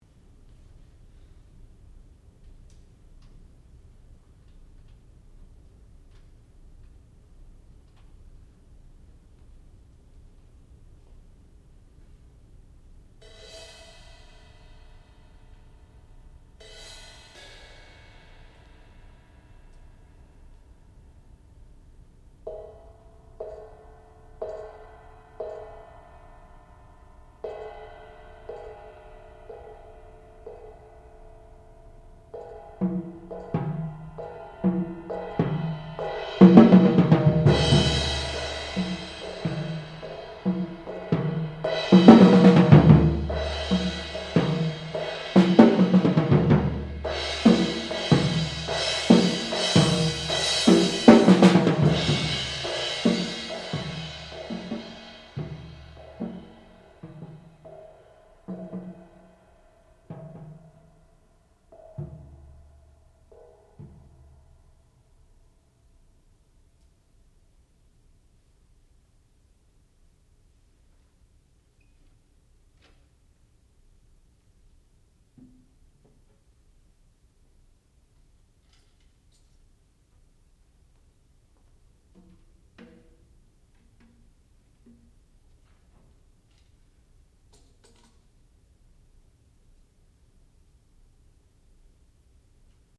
sound recording-musical
classical music
percussion
piano
Junior Recital